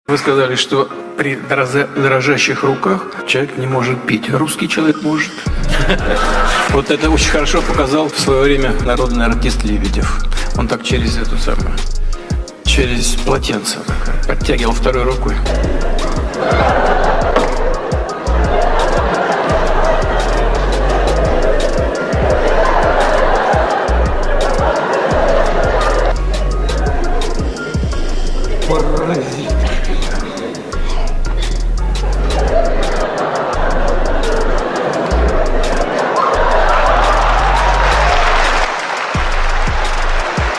Гениальная Смекалка Русских! интервью Путина о политике России!